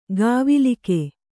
♪ gāvilike